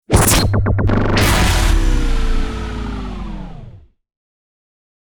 FX-015-IMPACT-COMBO.mp3